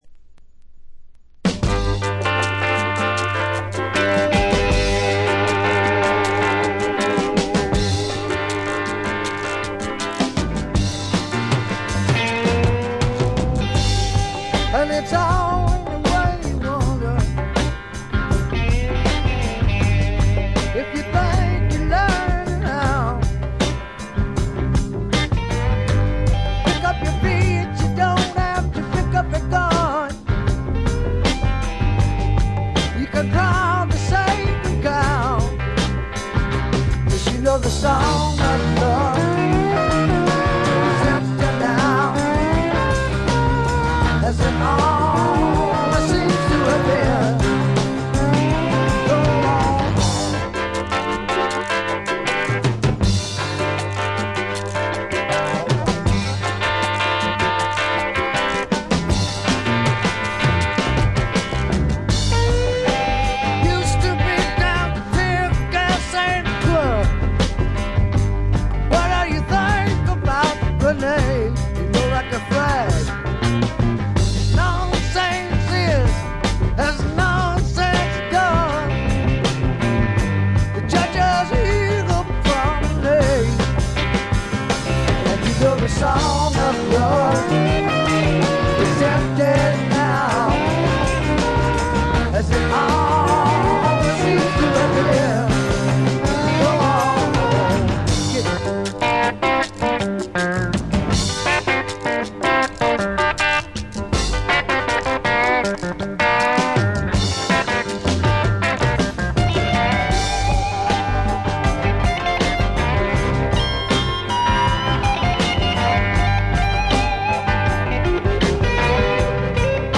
驚くほど骨太のロック・アルバムです！
エレクトリック・ギターによるロック・サウンドが凝縮されています。